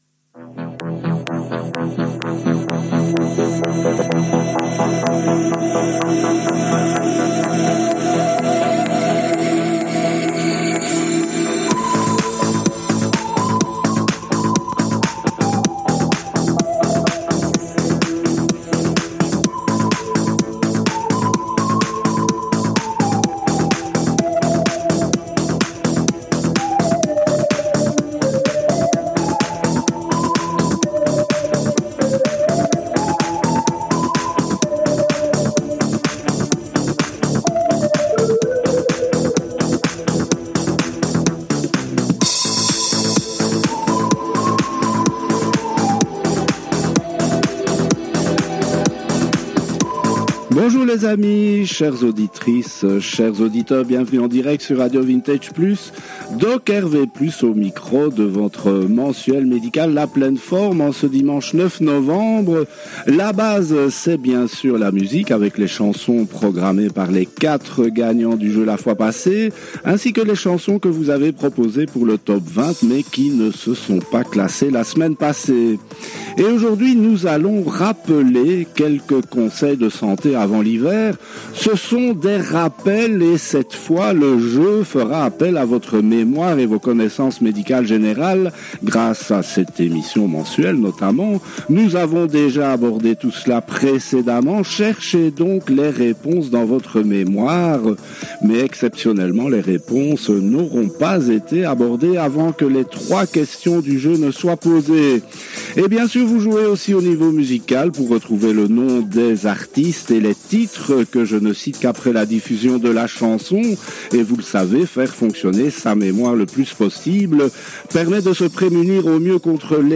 Emission mensuelle sur un sujet médical en musique et avec des jeux de mémoire